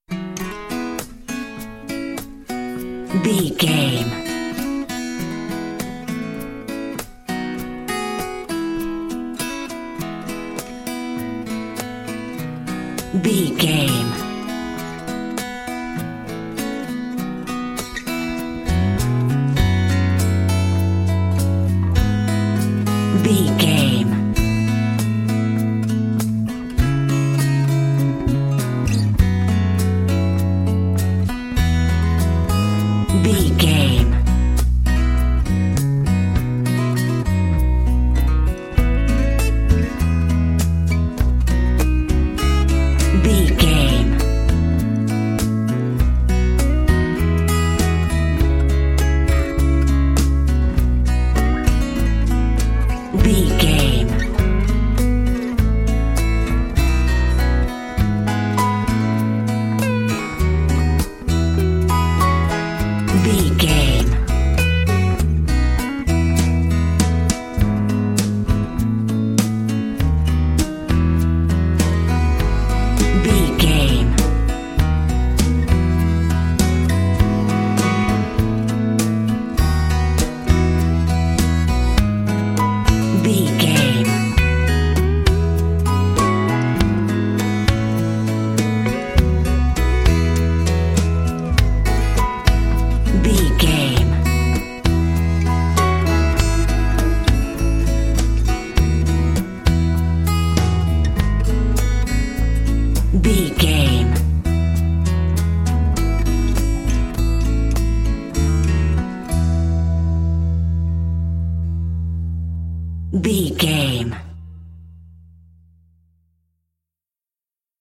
Ionian/Major
drums
acoustic guitar
piano
violin
electric guitar